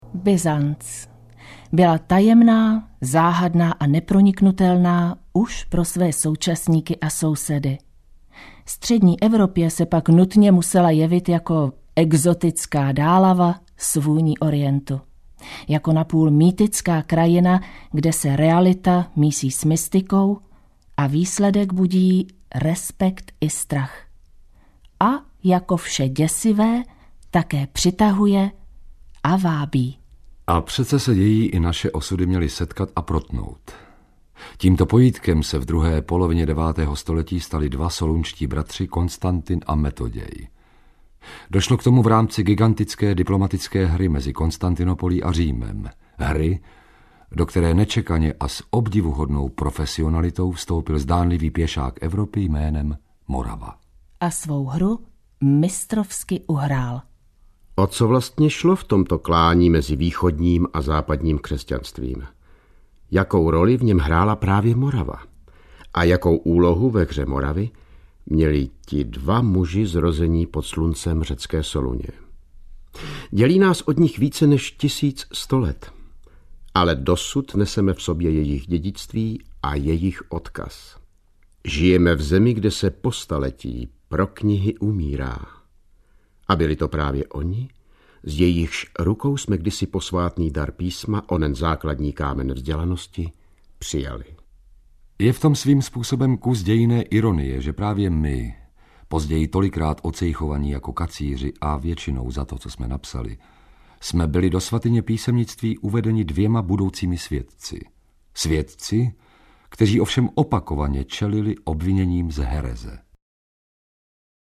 Svatí kacíři audiokniha
Audioverze románu, jehož ústředními postavami románu na pomezí literatury faktu a beletrie jsou soluňští bratři Konstantin (Cyril) a Metoděj. Příběh jejich velkomoravské mise je zde podáván jako součást historicky věrného obrazu doby.
Ukázka z knihy